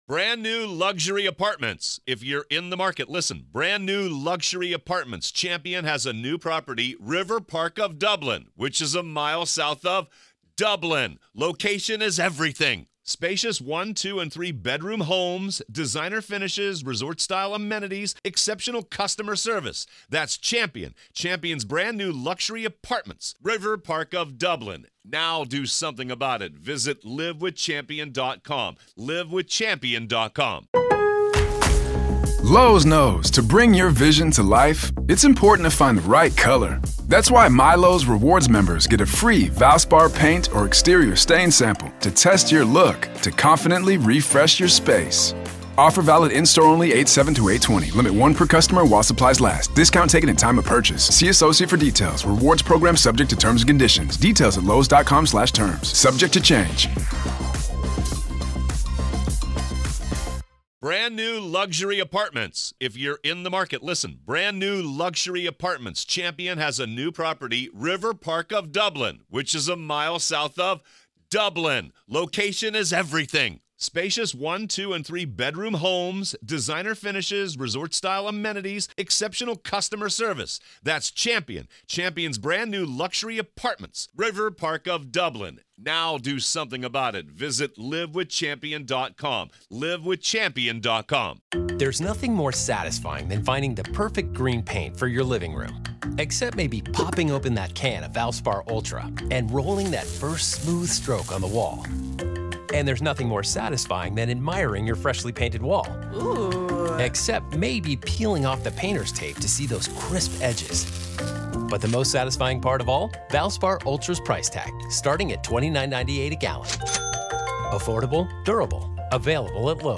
Closing Arguments-Raw Court Audio-NEVADA v. Robert Telles DAY 9 Part 2